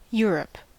Ääntäminen
Ääntäminen US NZ : IPA : /ˈjʊ(ə)ɹəp/ UK : IPA : /ˈjʊə.ɹəp/ IPA : /ˈjɔːɹəp/ US : IPA : /ˈjʊɹ.əp/ IPA : /ˈjɝəp/ Tuntematon aksentti: IPA : /ˈjɝp/ Lyhenteet ja supistumat eur.